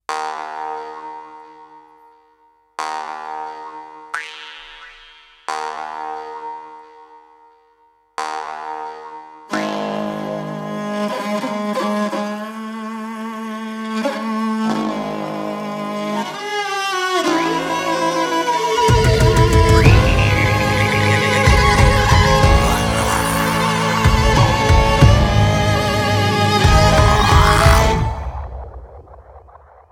• Metal